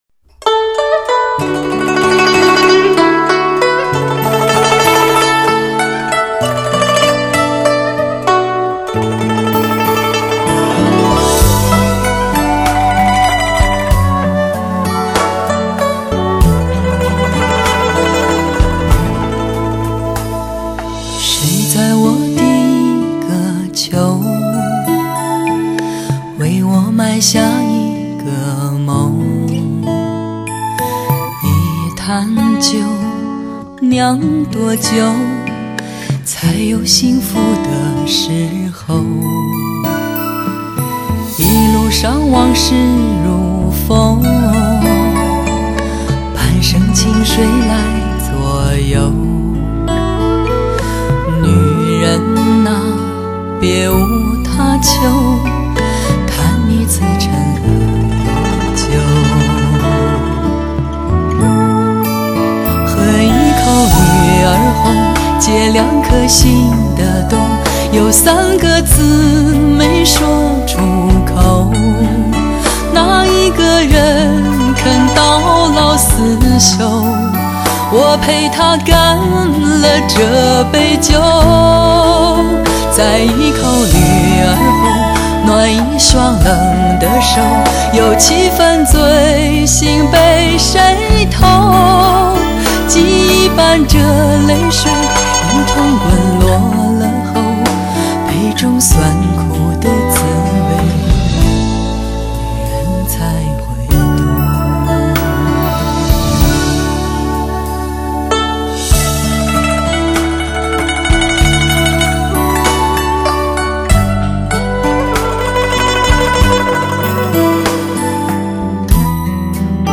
DTS ES6.1多声道高清测试碟
本张专辑从策划、编曲到录音制作就考虑到DTS-ES多声道音频技术的应用，从录音初始就
严格秉承DTS多声道的制作要求，采用多声道分轨录音，对于声音的采集完全做到每一个乐